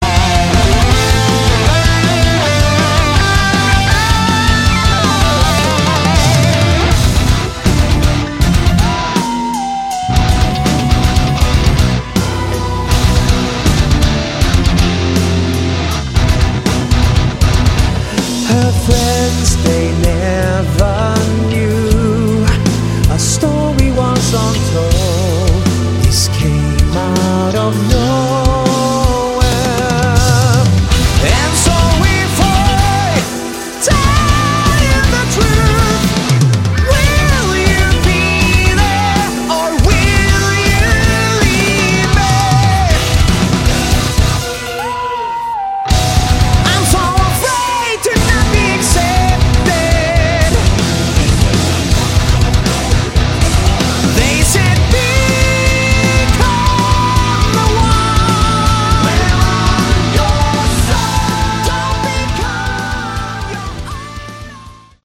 Category: Prog Rock